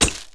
wrench_hit_glass2.wav